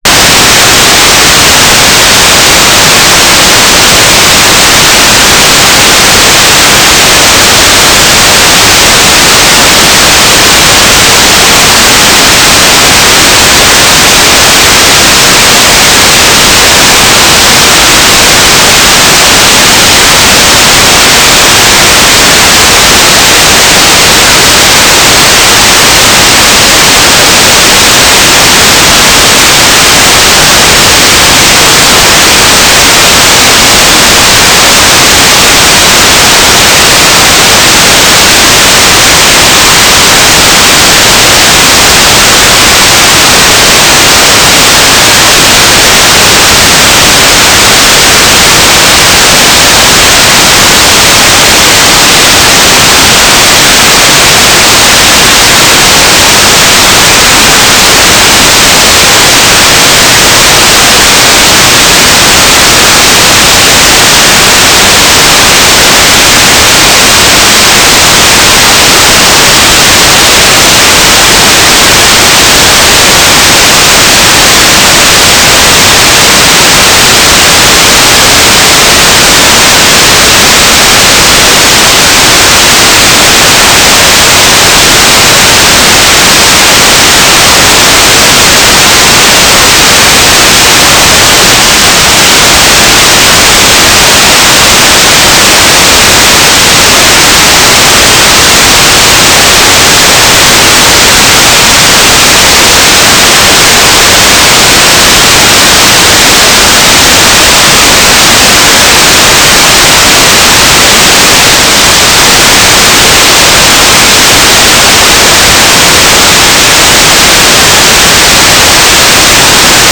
"transmitter_mode": "GMSK",